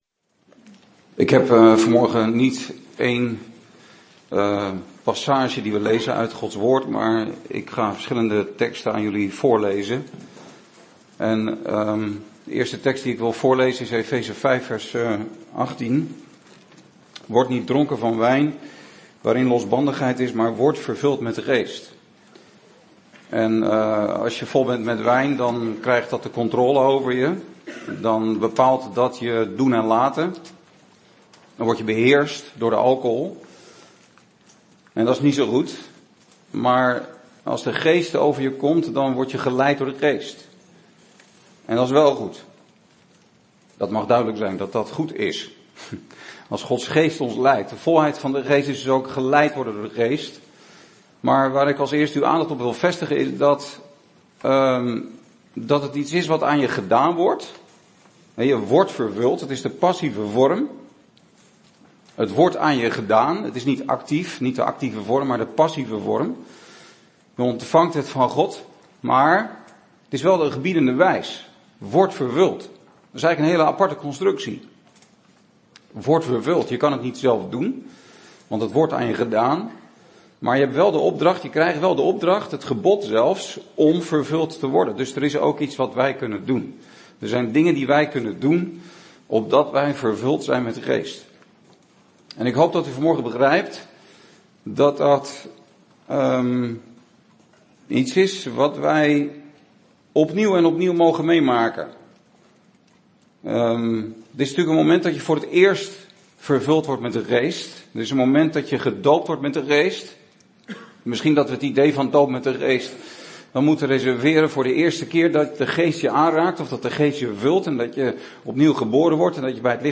Een preek over 'Hoe worden wij vervuld met de Heilige Geest?'.